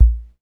TR-55 KICK 2.wav